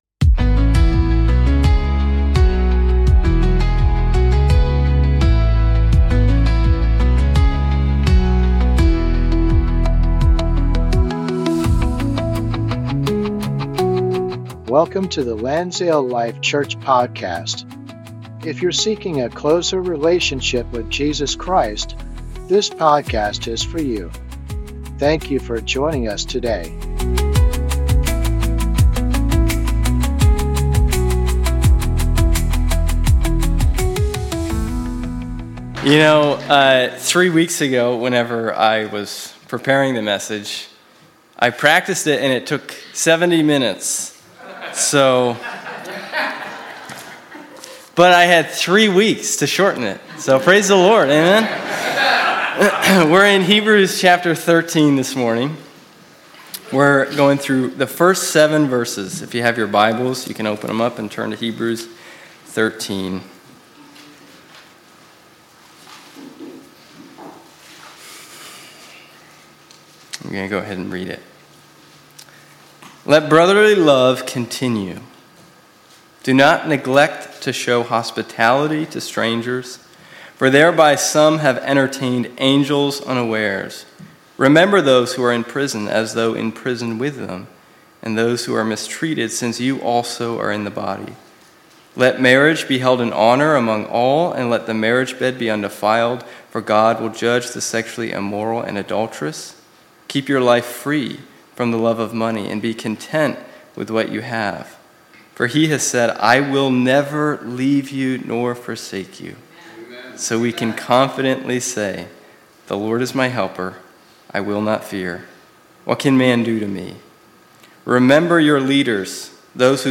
Sunday Service - 2026-02-01